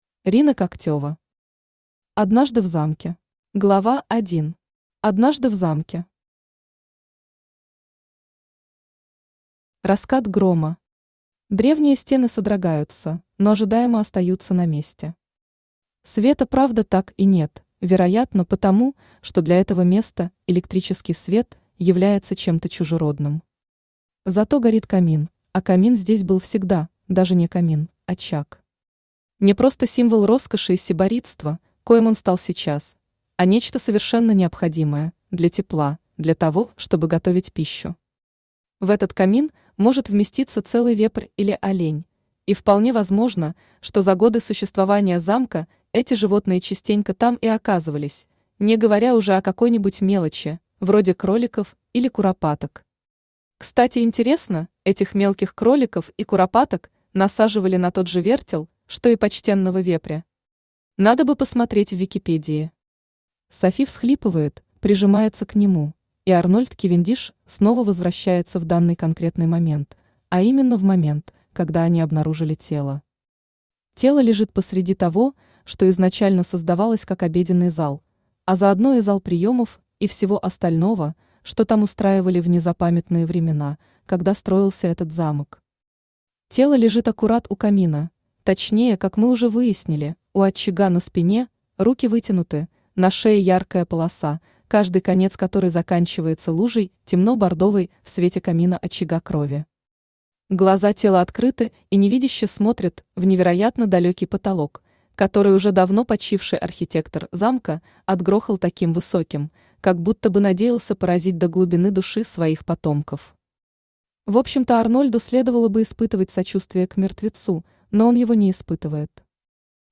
Aудиокнига Однажды в замке Автор Рина Когтева Читает аудиокнигу Авточтец ЛитРес.